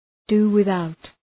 do-without.mp3